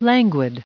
added pronounciation and merriam webster audio
1647_languid.ogg